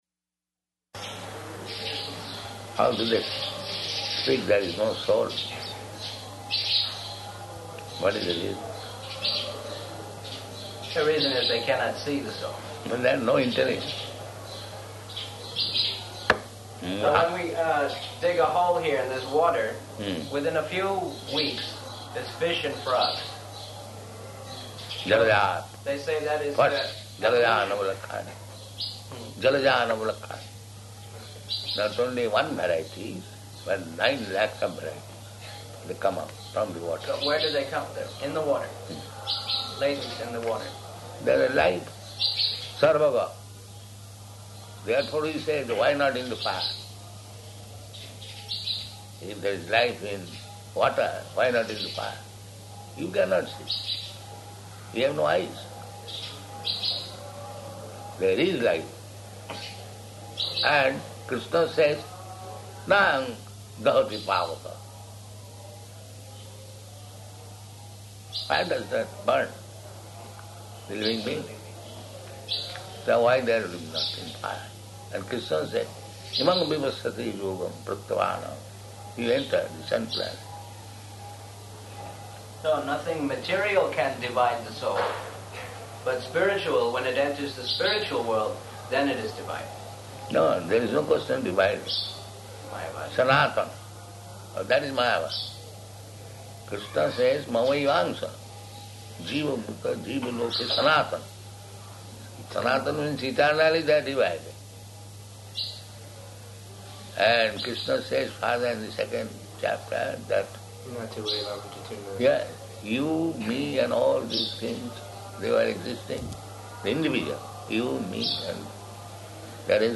Evening Darśana --:-- --:-- Type: Conversation Dated: February 19th 1977 Location: Māyāpur Audio file: 770219ED.MAY.mp3 Prabhupāda: How do they speak there is no soul?